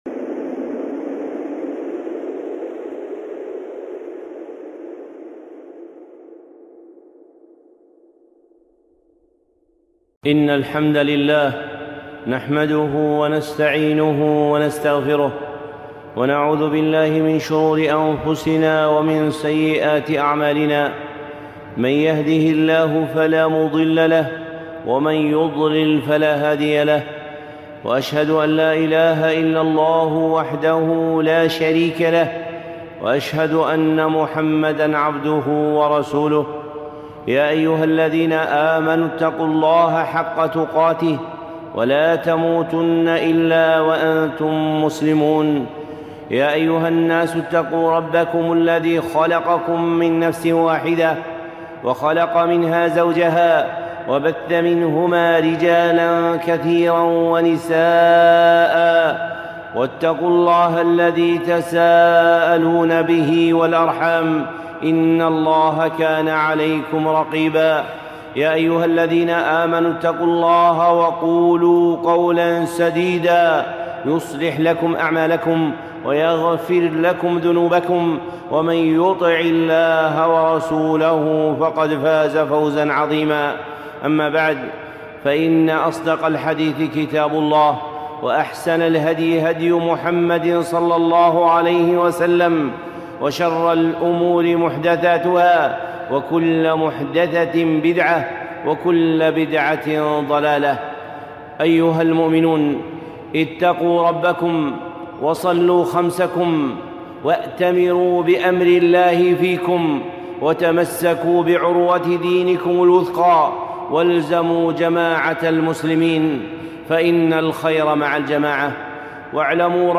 خطبة (تعزيز الأخوة الإيمانية)